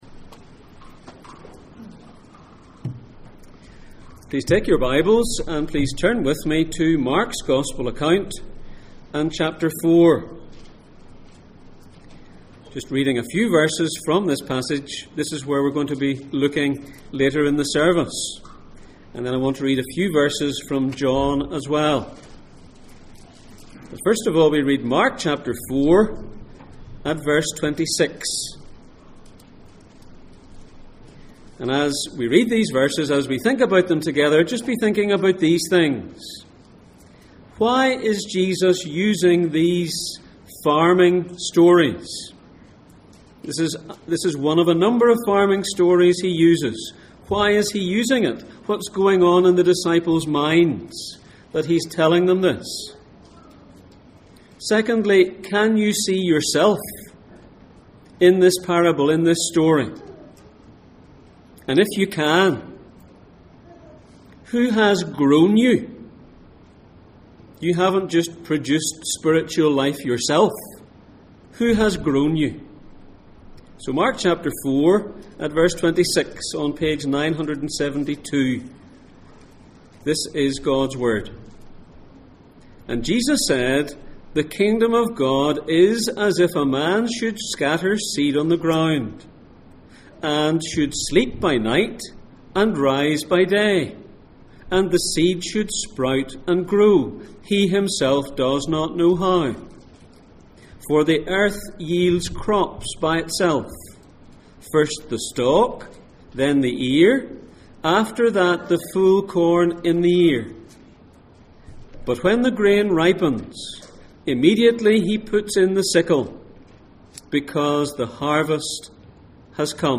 Mark Passage: Mark 4:26-29, John 17:12-19 Service Type: Sunday Morning %todo_render% « 4 types of Hearer Small start